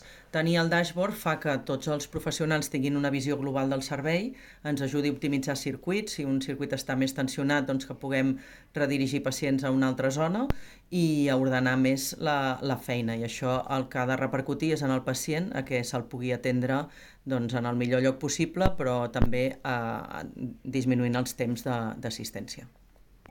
DECLARACIÓ DE LA DRA.